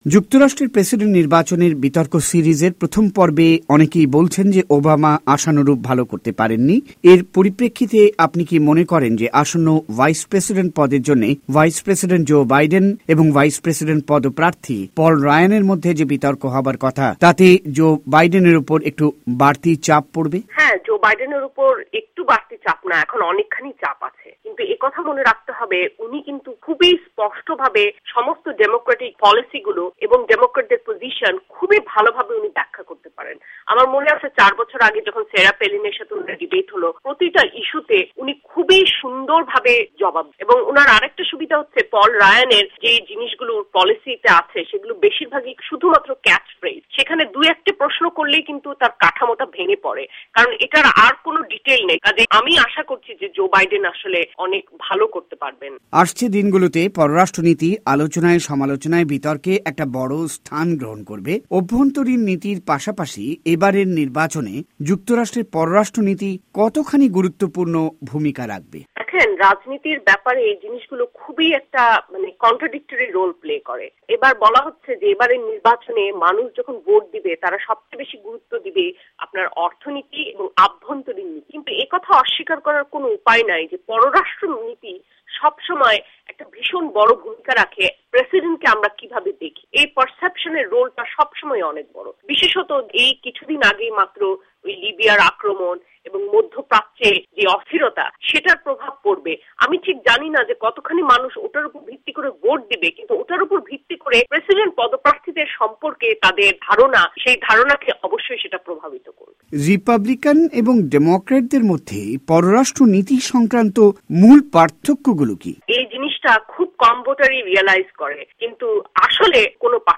সাক্ষাতকার